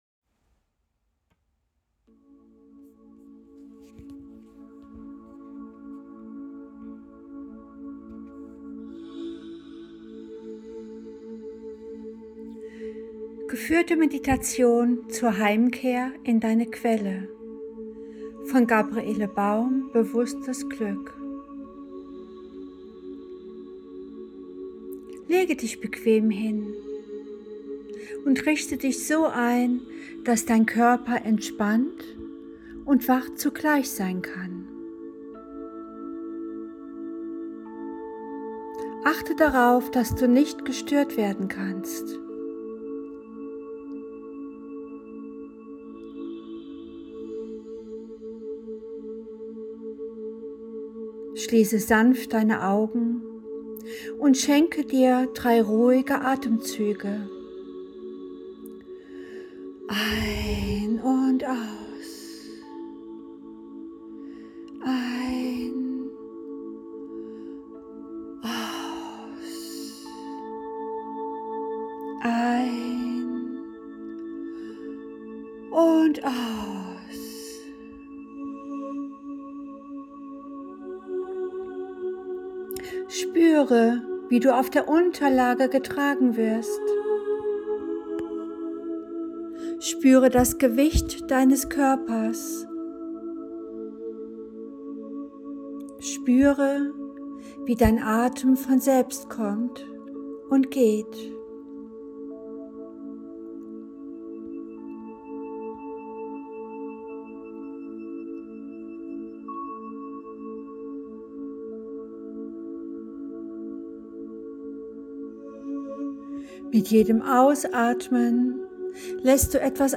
Kapitel 10 Die Rückkehr zu deiner inneren Quelle – mit geführter Meditation